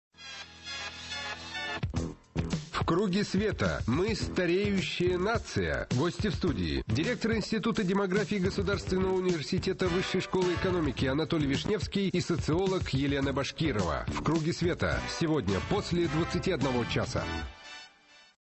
Аудио: анонс –